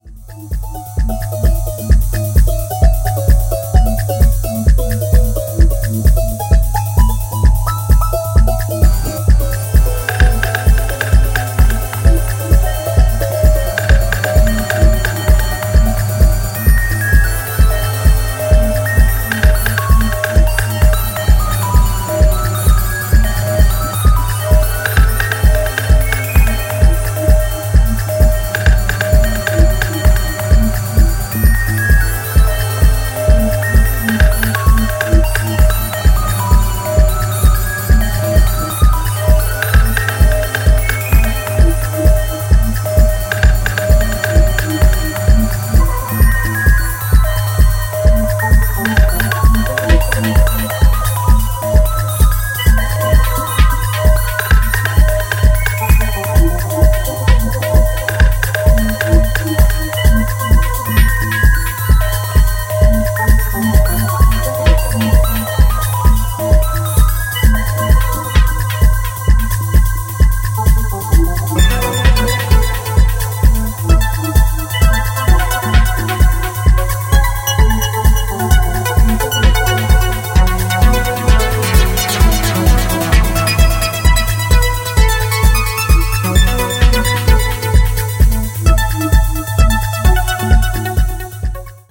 where near bottomless techno grooves get run through with